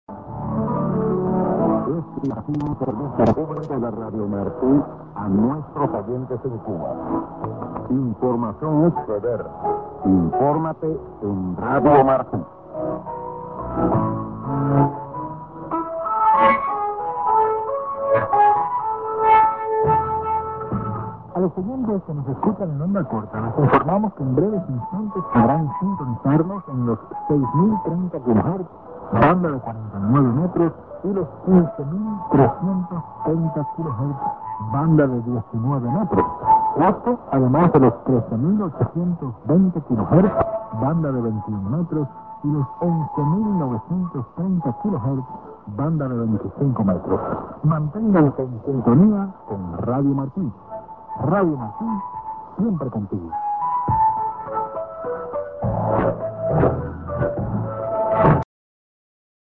New Freq. End ST->ID+SKJ(man)